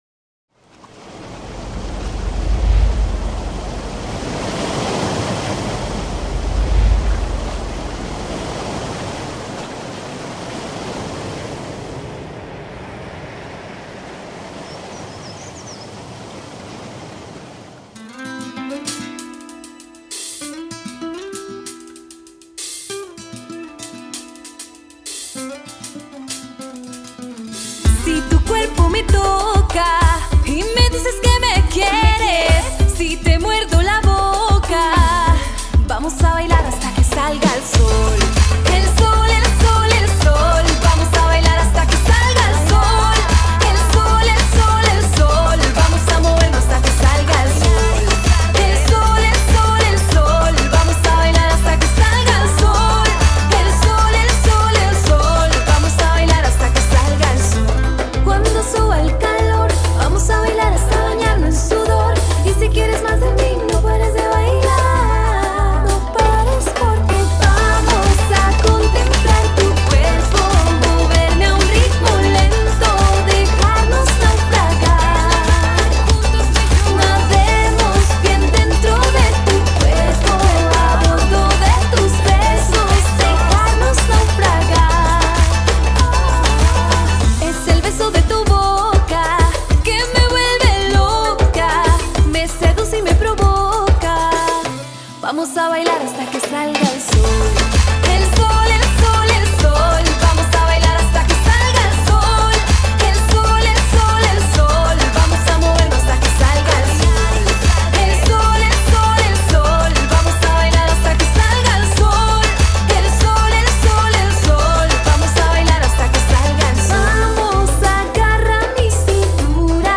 cantante y bailarina colombiana